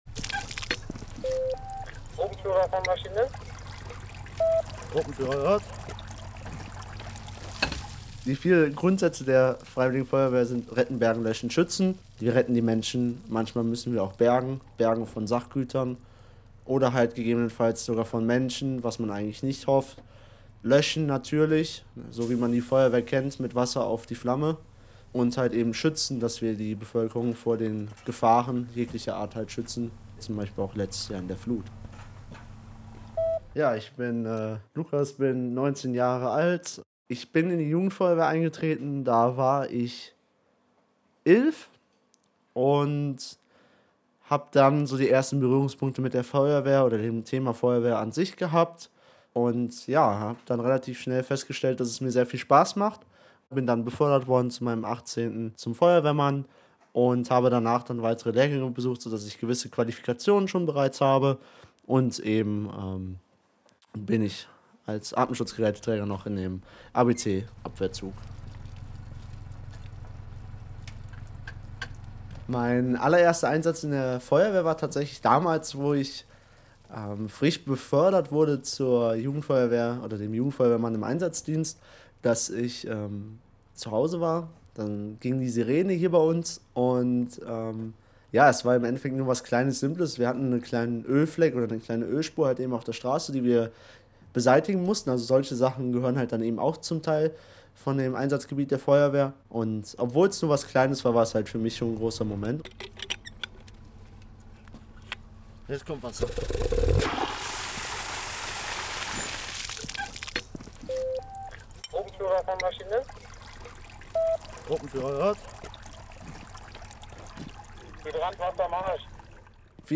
Dieser Radiobeitrag zum Thema Ehrenamt entstand im Rahmen einer Projektwoche an der Hochschule Bonn-Rhein-Sieg und wurde mit dem Publikumspreis am Tag des offenen Projekts ausgezeichnet. Danach wurde der Beitrag noch im Lokalmagazin von Studio Eins auf Radio Bonn-Rhein-Sieg gespielt.